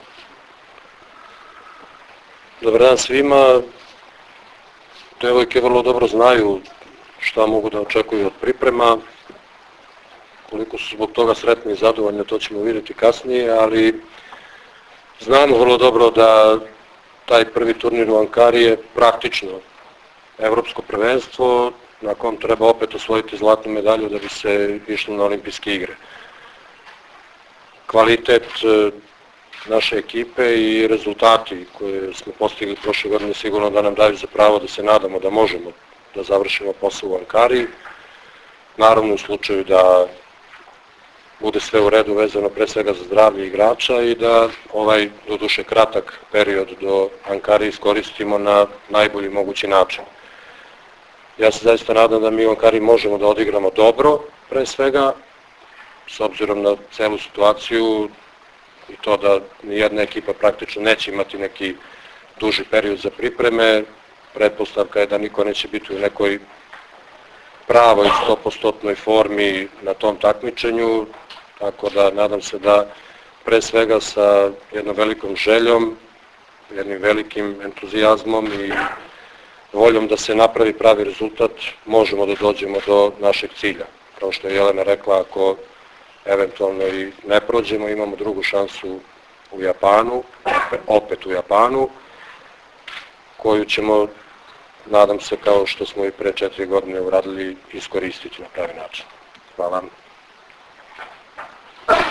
IZJAVA ZORANA TERZIĆA 1